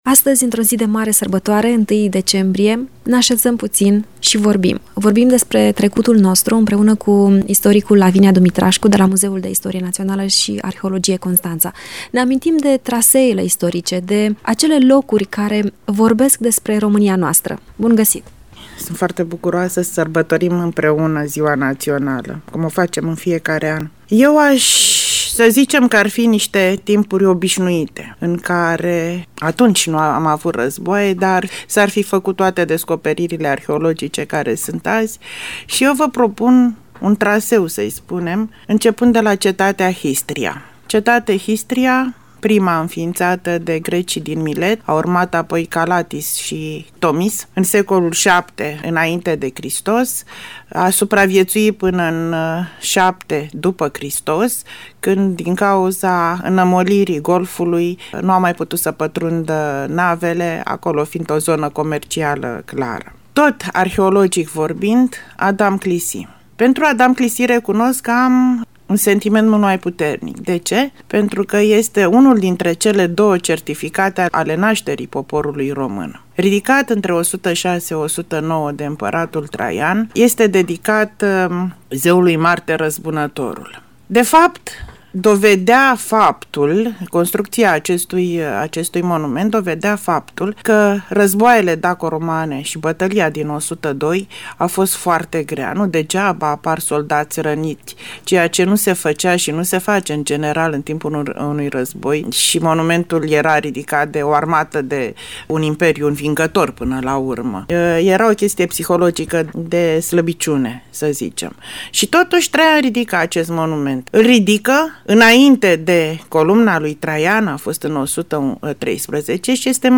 Un dialog